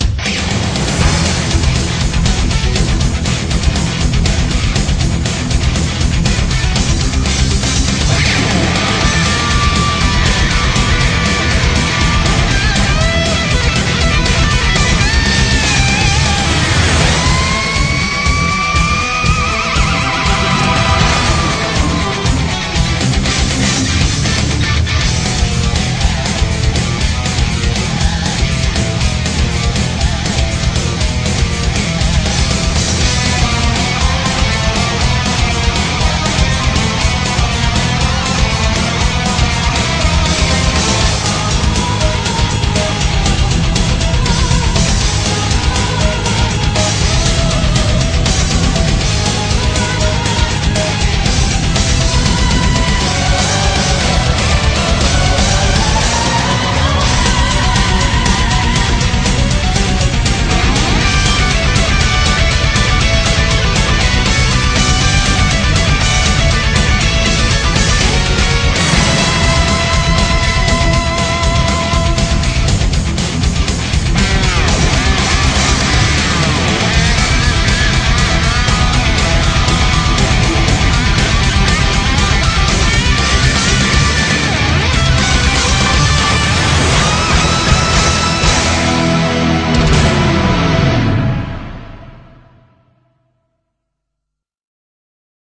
BPM239